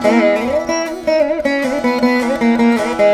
152C VEENA.wav